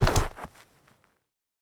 Scuffed Shot A.wav